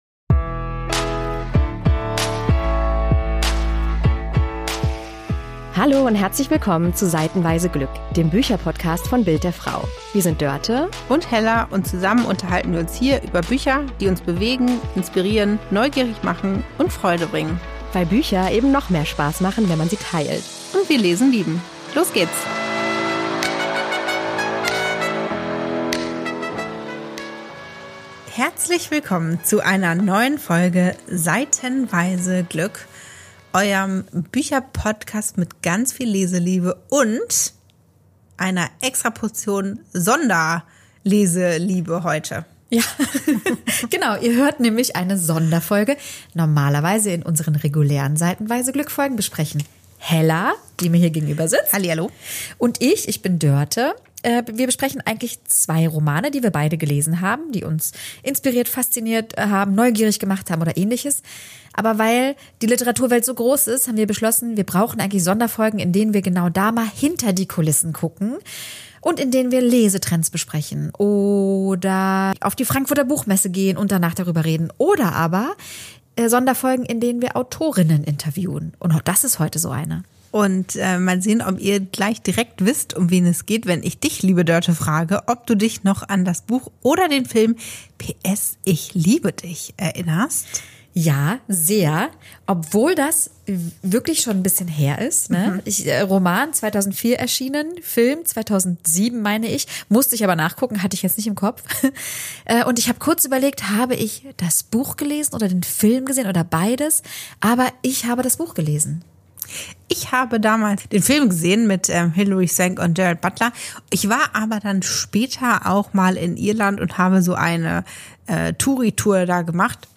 123 Sonderfolge: P.S. Ich liebe dich-Autorin Cecelia Ahern im Gespräch ~ Seitenweise Glück Podcast